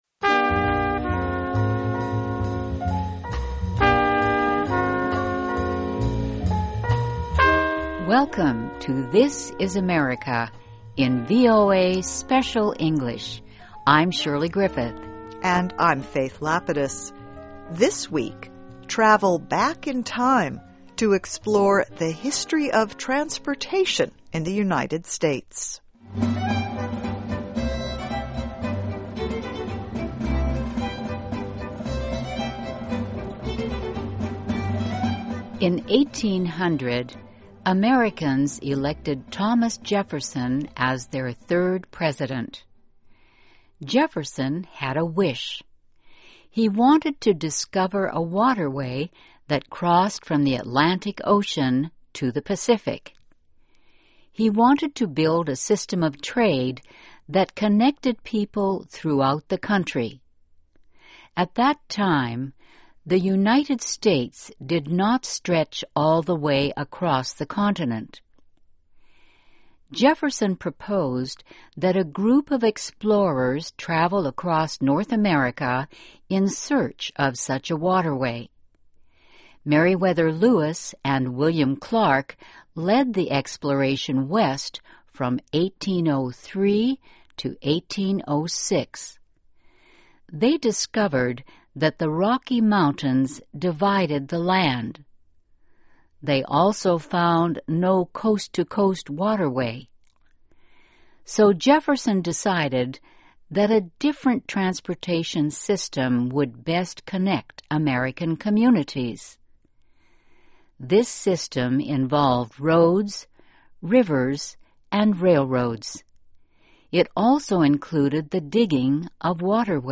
Welcome to THIS IS AMERICA, in VOA Special English.